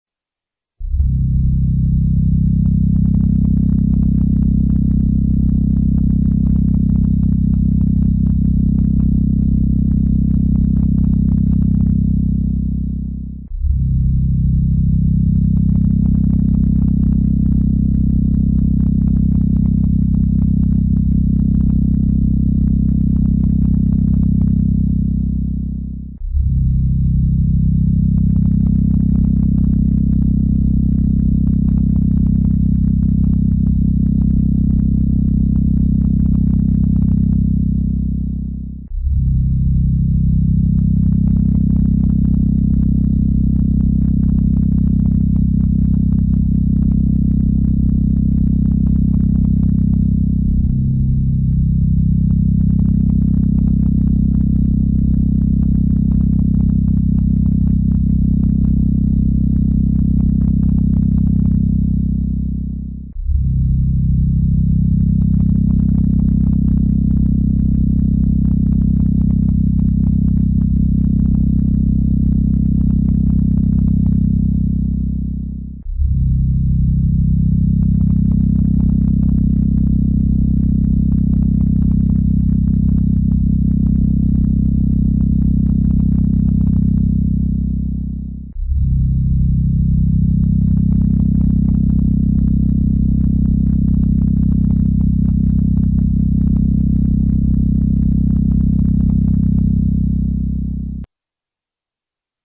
方法一、使用震动音乐清理灰尘
播放上面的这个特别定制的清灰除尘声波，将灰尘振动出来，这个原理类似于苹果手表的排水功能，我们可以试着多播放几次，平时有时间就拿出来播放一下，勤清理能够防止灰尘堆积。